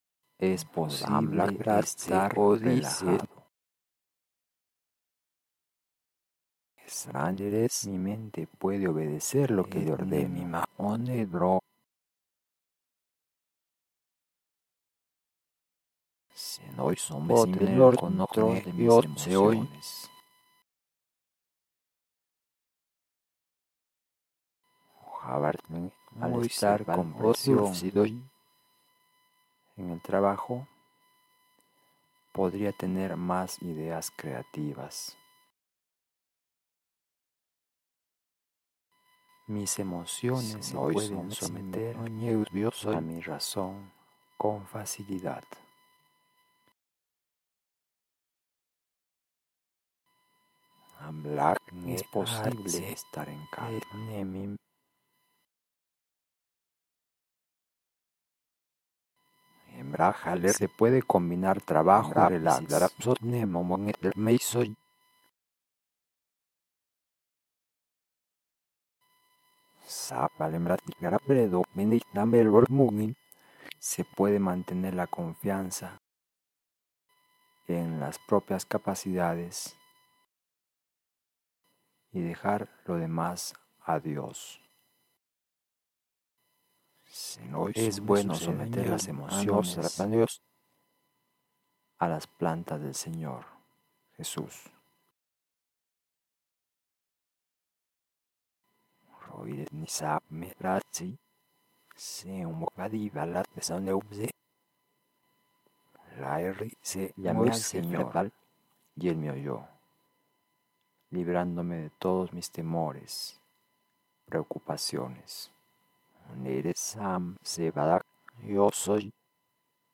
Grabaciòn son mùsica de fondo
estres_voz_sola.mp3